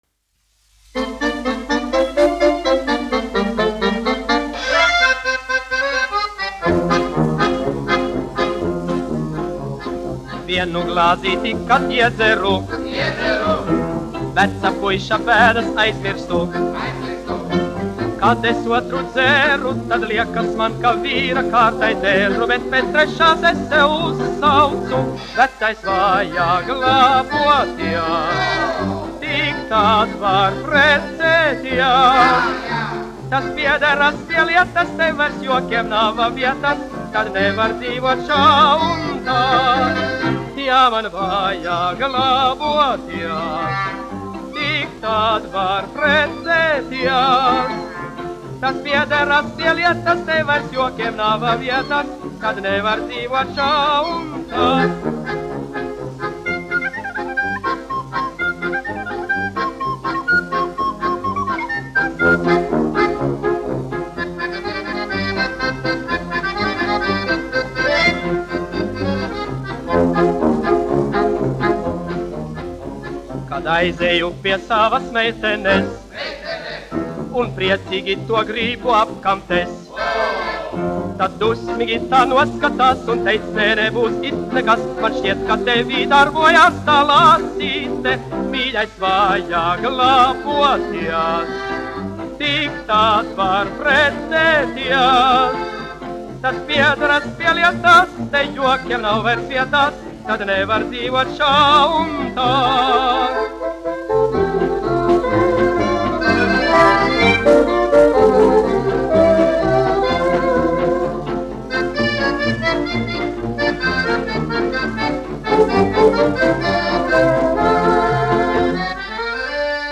1 skpl. : analogs, 78 apgr/min, mono ; 25 cm
Fokstroti
Populārā mūzika